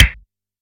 softl-hitnormal.ogg